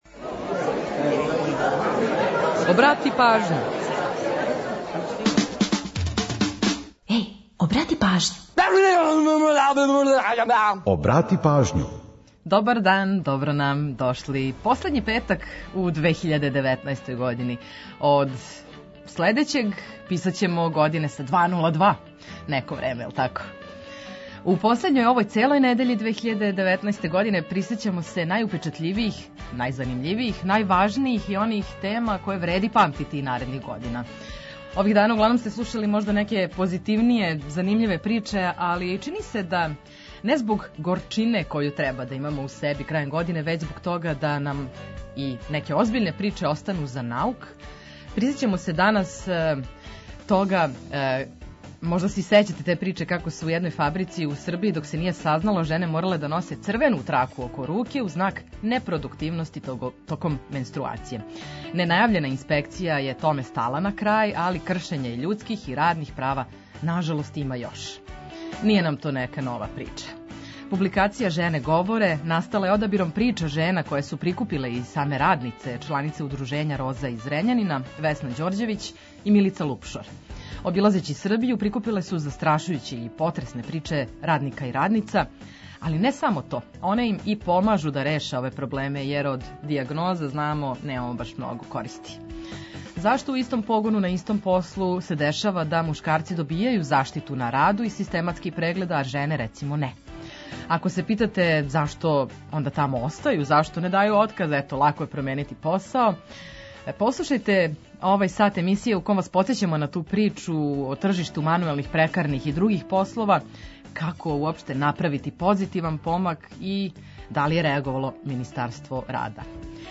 Чућете „Приче о песмама”, пола сата музике из Србије и региона, а упозоравамо и на евентуалне саобраћајне гужве.